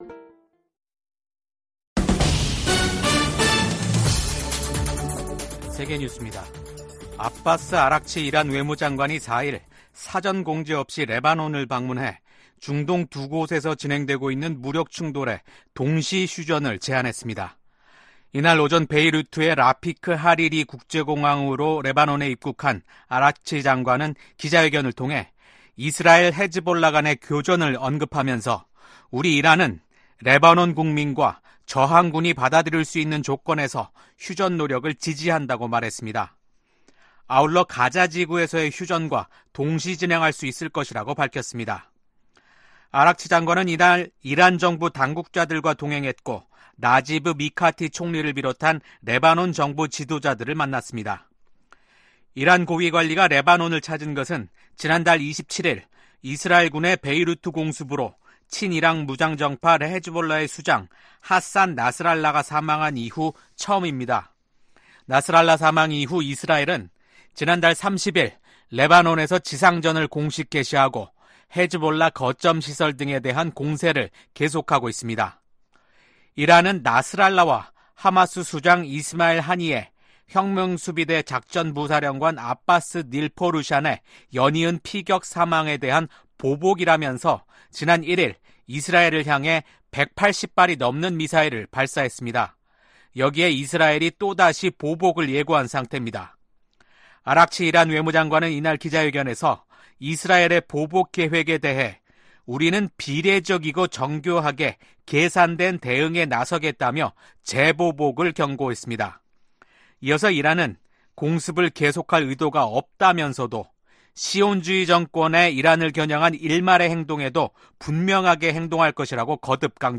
VOA 한국어 아침 뉴스 프로그램 '워싱턴 뉴스 광장' 2024년 10월 5일 방송입니다. 이시바 시게루 신임 일본 총리가 제안한 ‘아시아판 나토’ 구상에 대해 미국 하원의원들은 대체로 신중한 반응을 보였습니다. 김정은 북한 국무위원장은 윤석열 한국 대통령을 실명으로 비난하면서 미국과 한국이 북한 주권을 침해하려 할 경우 핵무기로 공격하겠다고 위협했습니다.